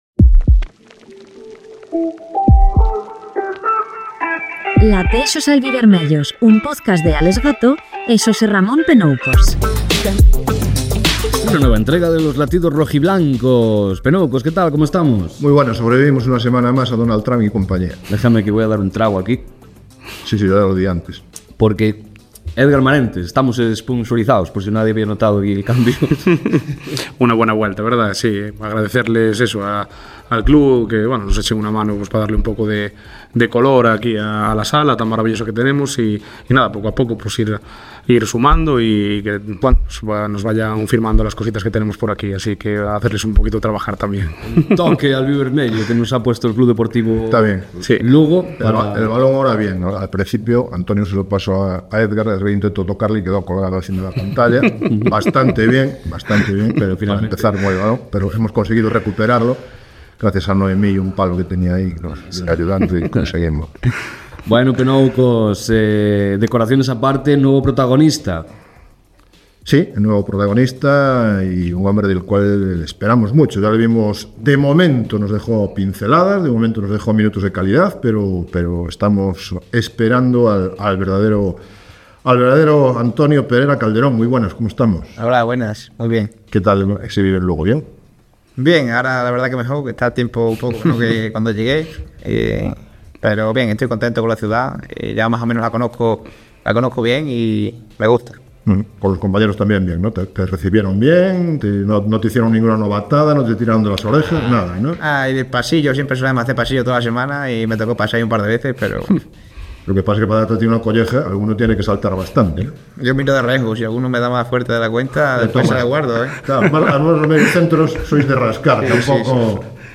Unha conversa tranquila, sincera e chea de fútbol.